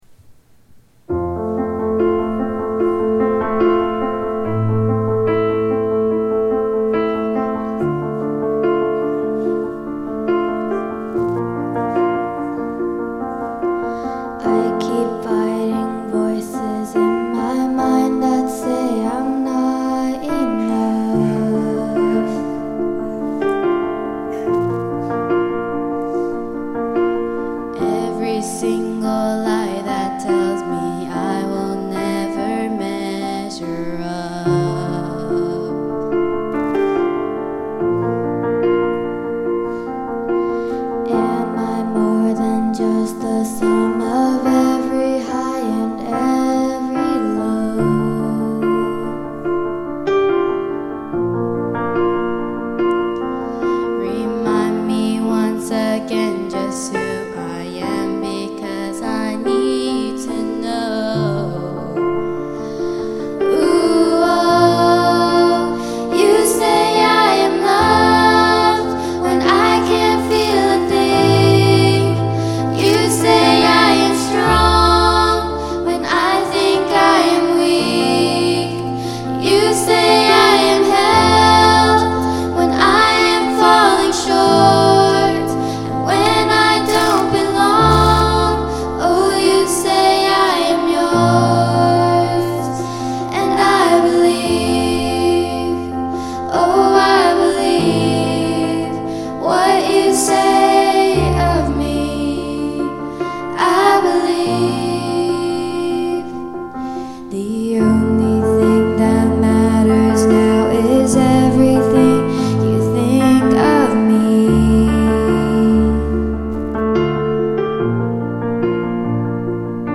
5월 12일 특송(5/12 Special Song)